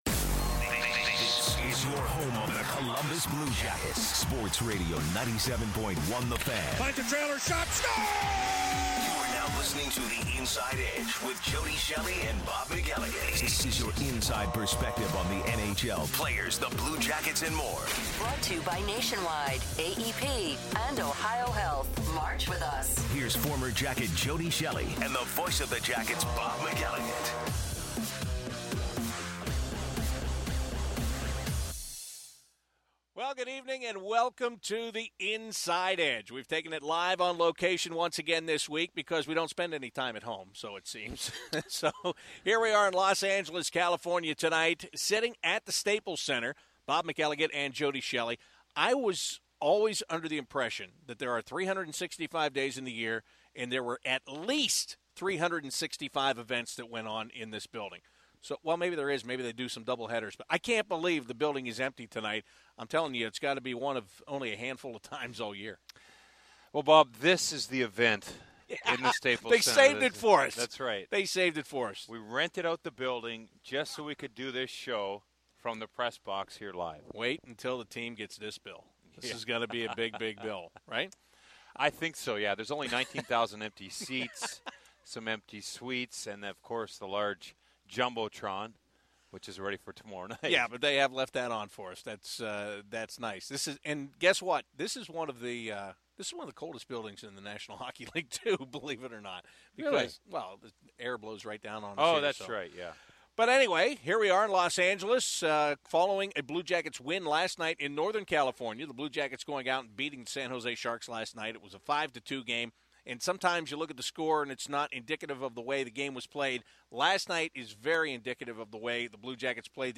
are live in Los Angeles at the Staples Center, previewing tomorrow night's contest against the Kings.